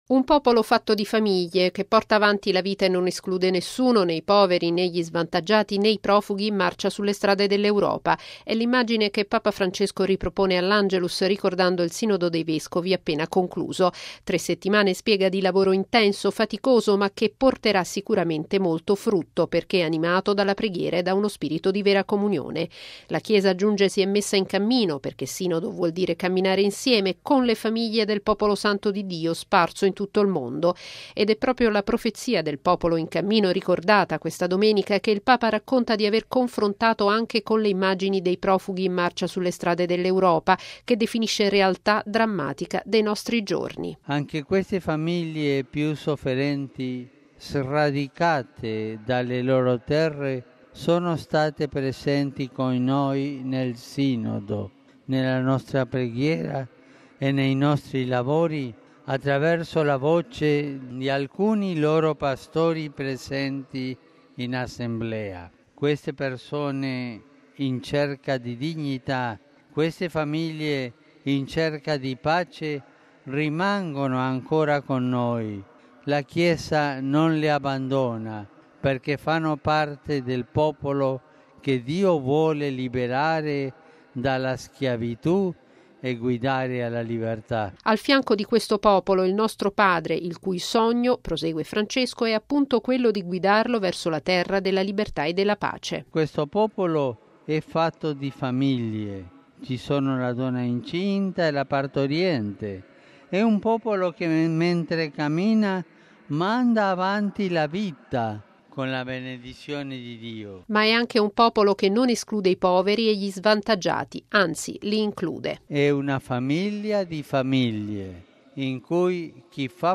Bollettino Radiogiornale del 25/10/2015